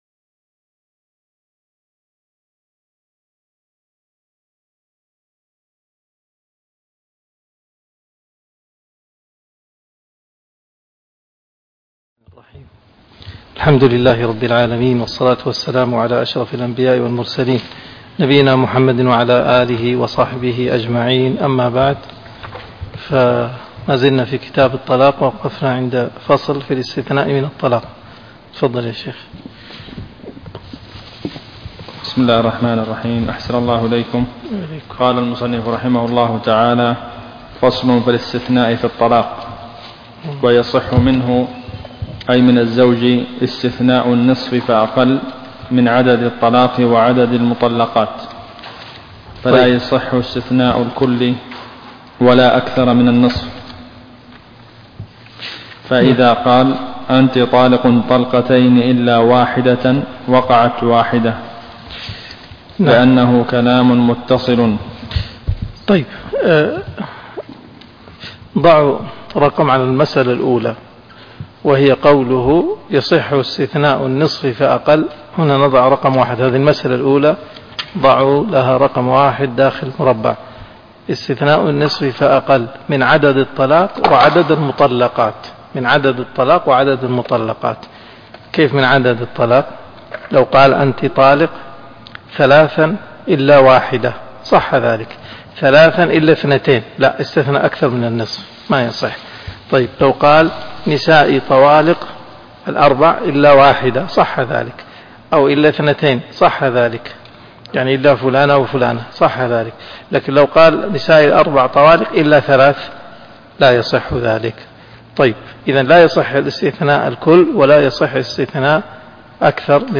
الدرس 131 (شرح الروض المربع)